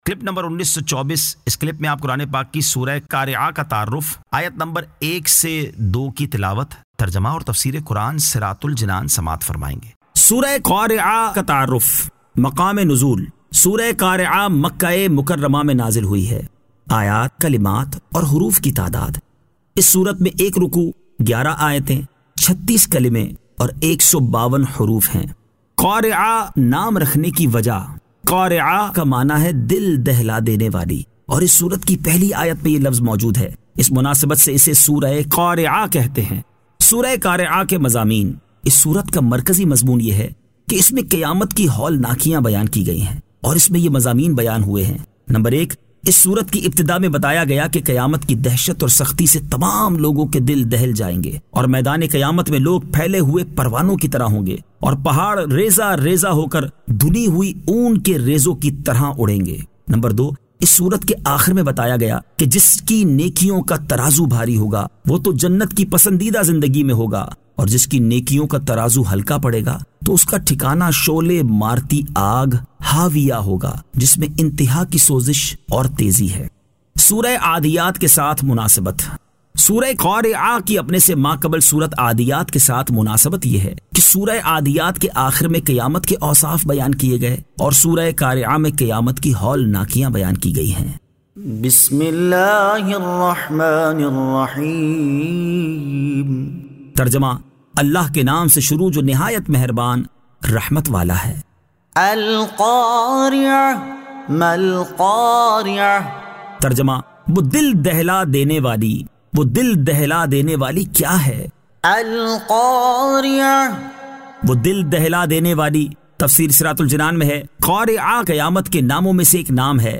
Surah Al-Qari'ah 01 To 02 Tilawat , Tarjama , Tafseer